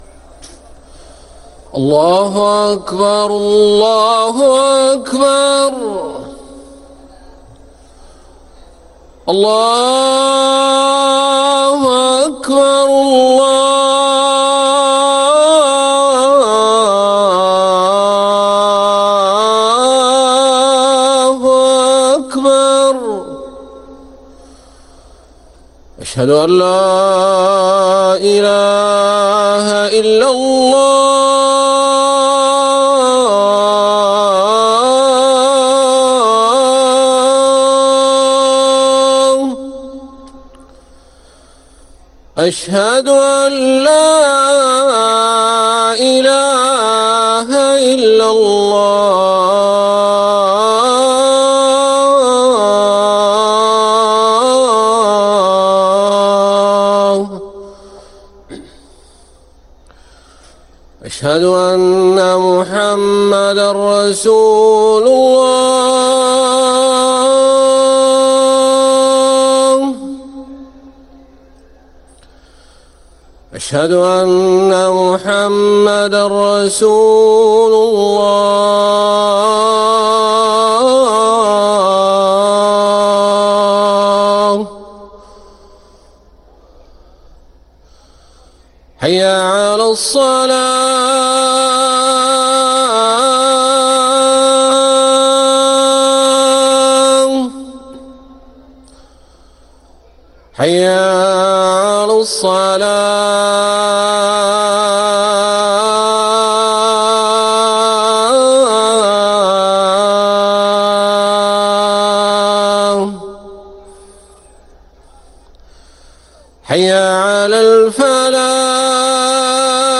أذان الفجر للمؤذن عصام خان الاحد 10 ربيع الثاني 1446هـ > ١٤٤٦ 🕋 > ركن الأذان 🕋 > المزيد - تلاوات الحرمين